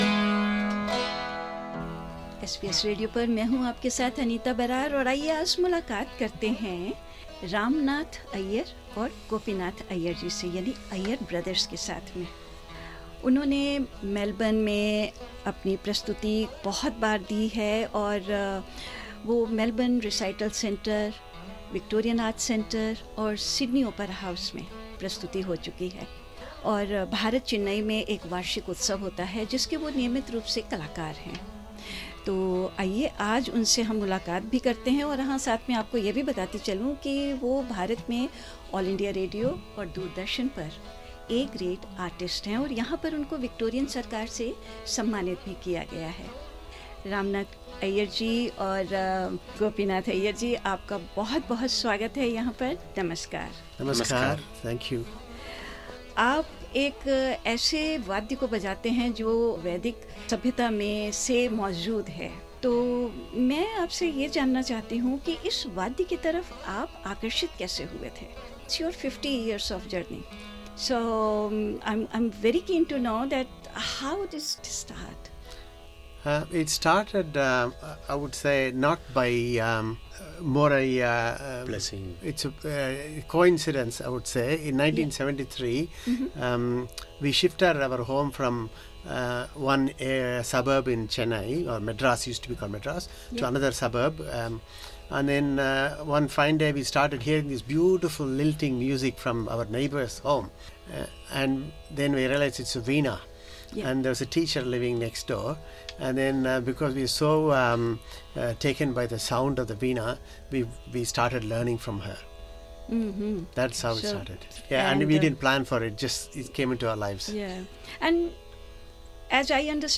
इस पॉडकास्ट में सुनिये इस वाद्य यंत्र की कुछ जानकारी और उनकी एक प्रस्तुति भी।